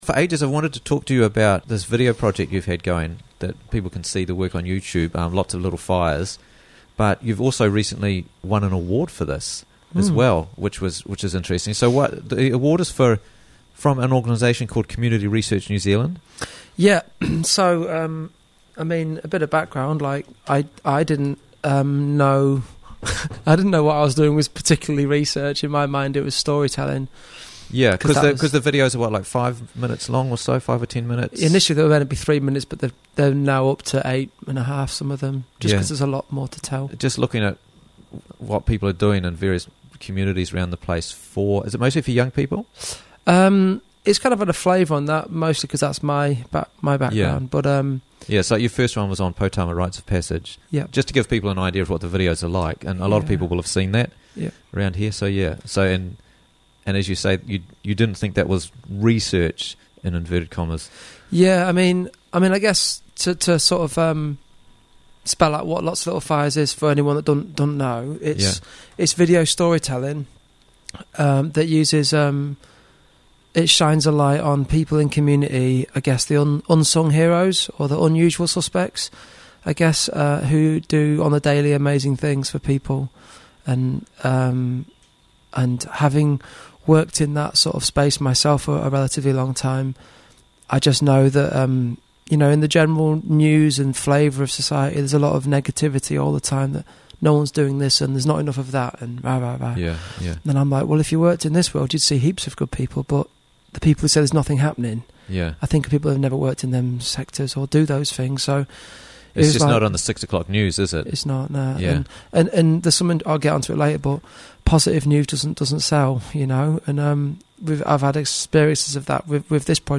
Interviews from the Raglan Morning Show